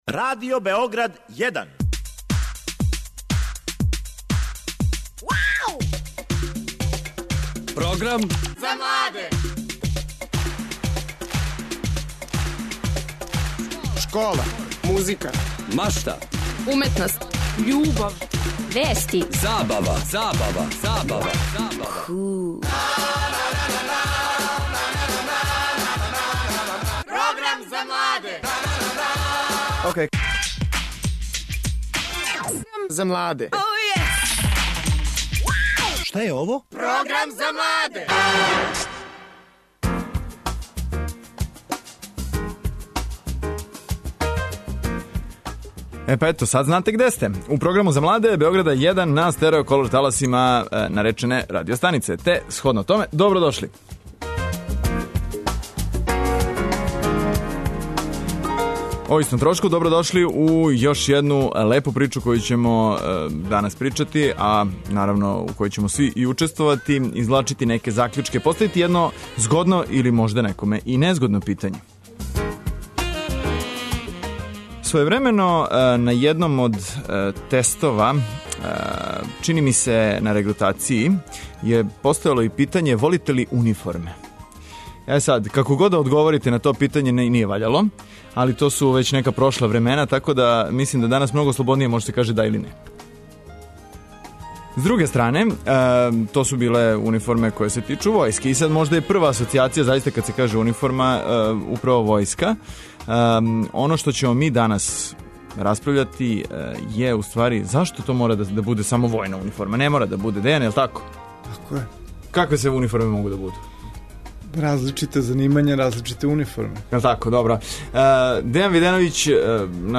Постављамо питање да ли треба униформе вратити у школе? Наши гости биће управо они људи који носе униформе - лекари, ватрогасци, полицајци...